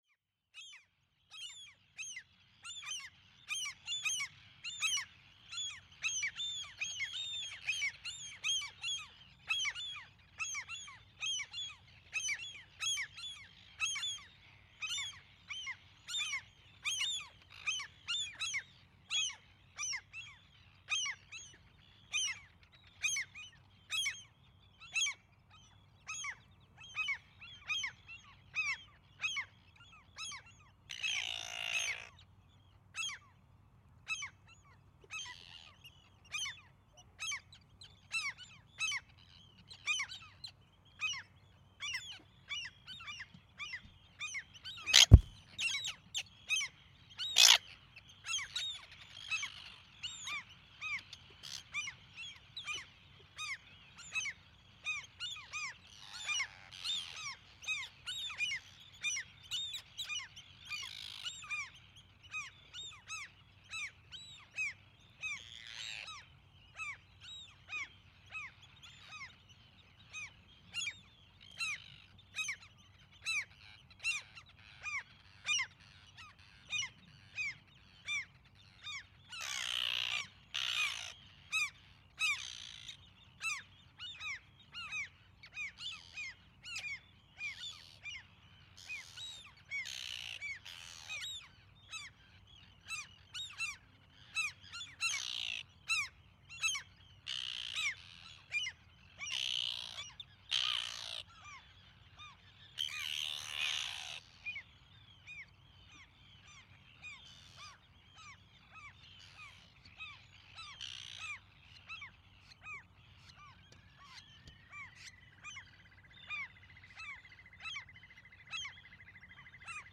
The bird colony at Hjalteyri
There is also a pretty big Arctic Tern colony which brings also many other bird species to the area.
It is 25 minutes of 6 hours long overnight recording. This is one of my recording where I probably should have used another microphones because of the noise source in the surrounding. In this case a „fan noise“ from the factory.
t881_bird_colony_hjalteyri.mp3